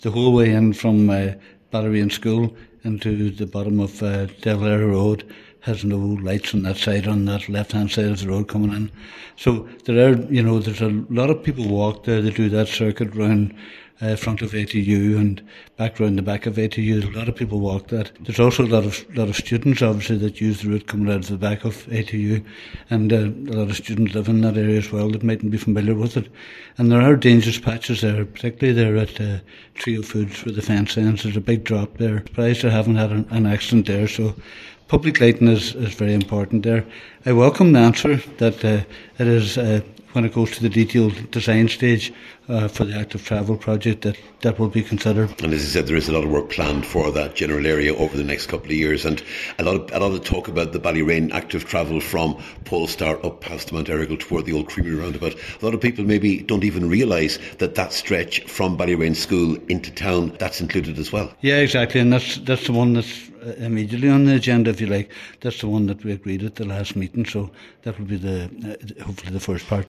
Cllr Kavanagh says with no lights on the left hand side of the road, there are safety issues to be considered now……..